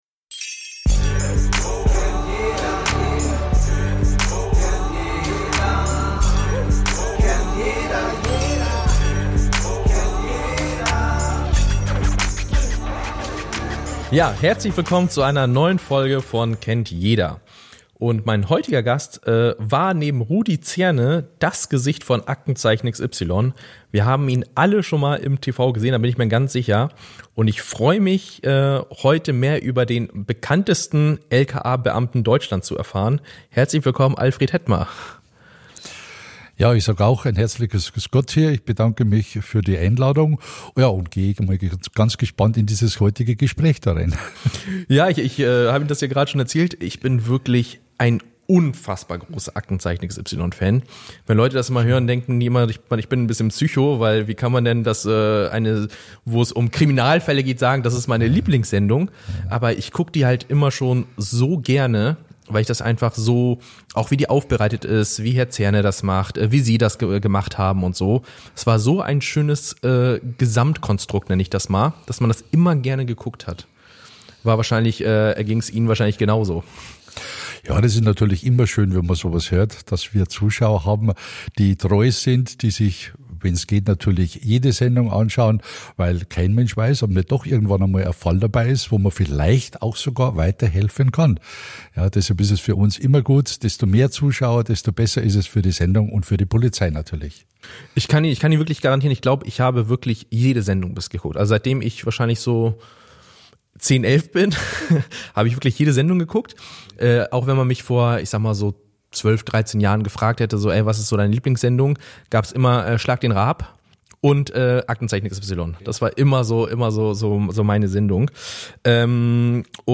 Ein spannendes, ehrliches und sehr persönliches Gespräch mit einem Mann, der Jahrzehnte lang im Dienste der Verbrechensaufklärung stand – im echten Leben und im Fernsehen.